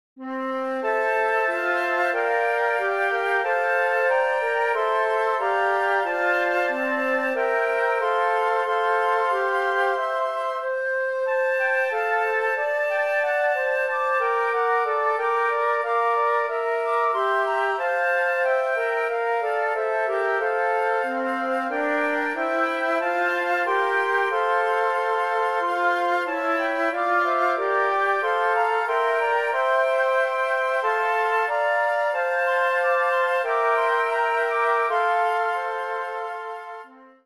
Besetzung: Flötenquintett
3 meditative Adventslieder für Flötenquartett